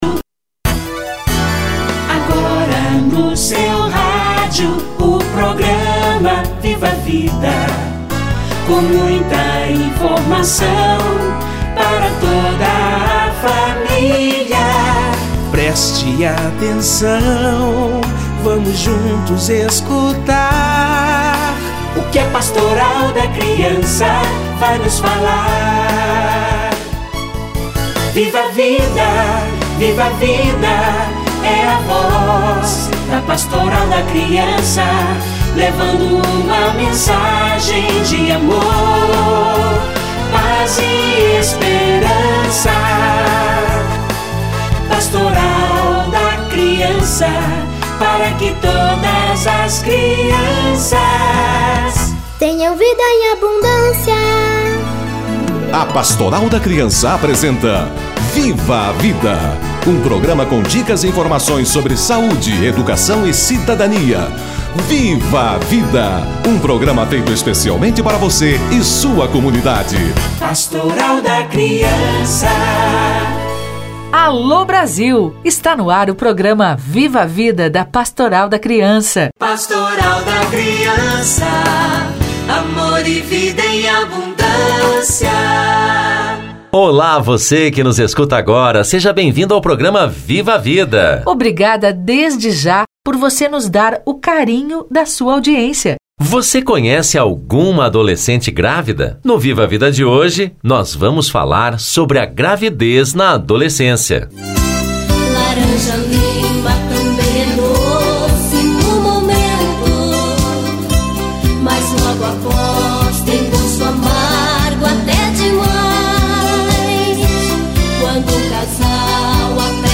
Gravidez na adolescência - Entrevista